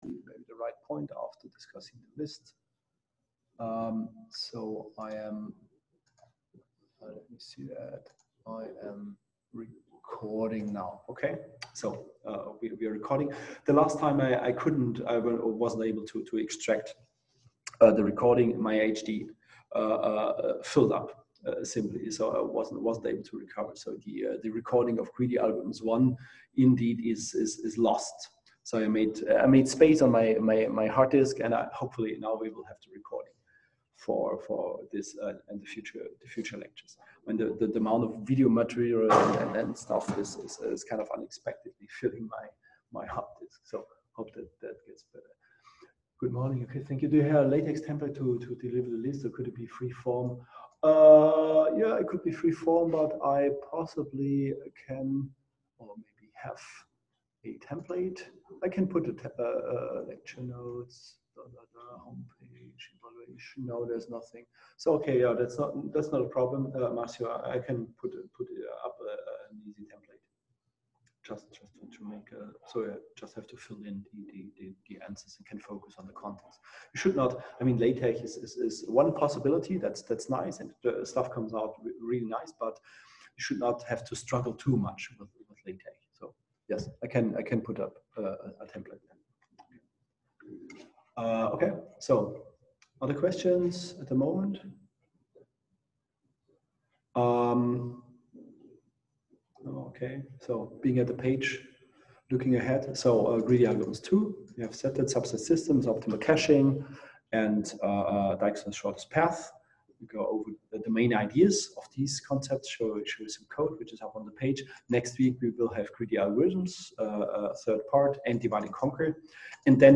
Online lecture